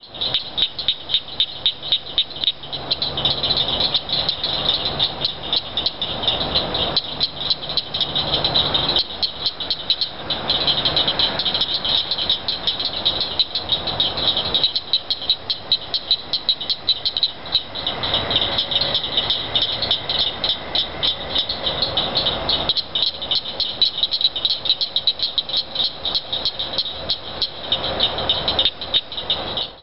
This is a chorus of Acris gryllus dorsalis calling from a pond not too far from a busy highway.
AprilCricketFrogs.mp3